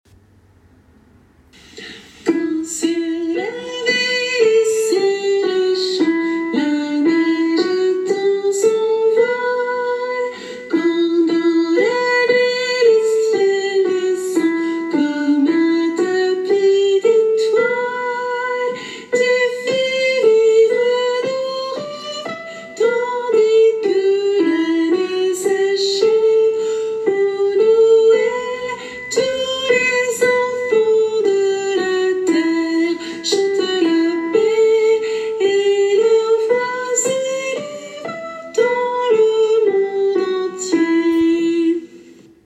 soprano et autres voix en arrière plan